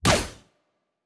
pgs/Assets/Audio/Sci-Fi Sounds/Weapons/Weapon 02 Shoot 1.wav at master
Weapon 02 Shoot 1.wav